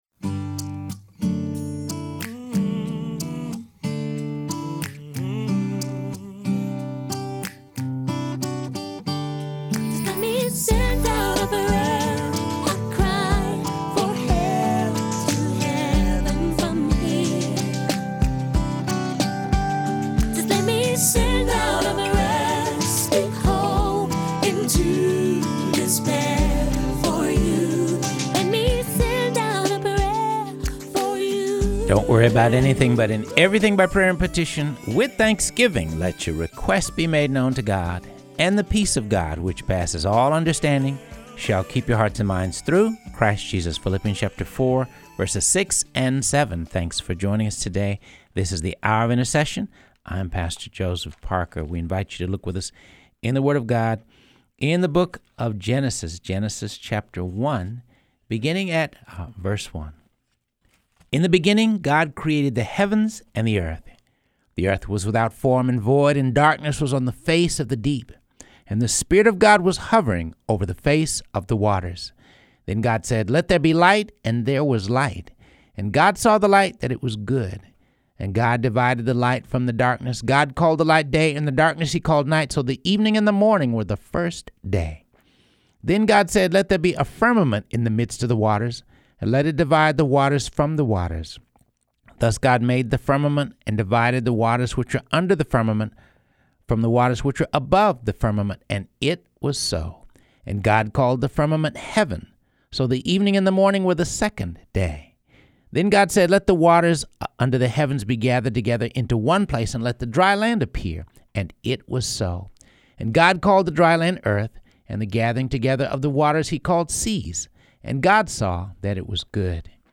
reading through the Bible